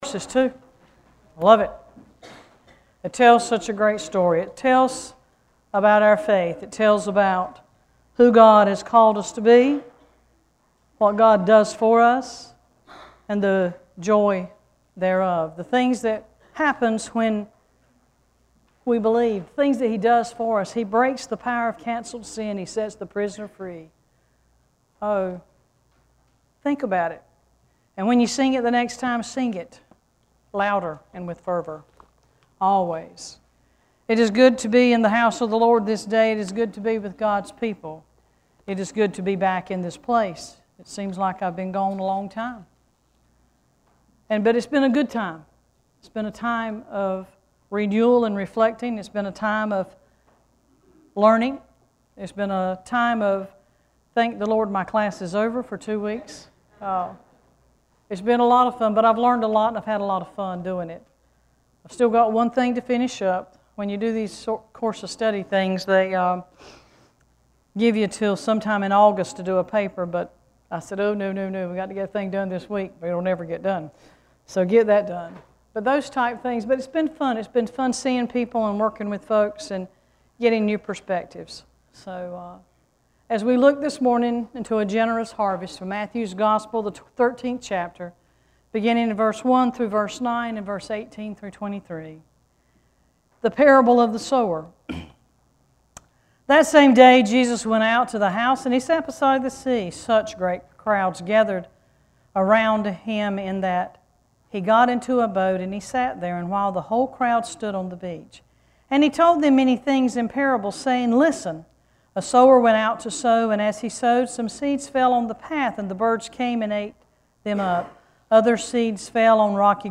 Worship Service 7-13-14: A Generous Harvest
7-13-14-scripture.mp3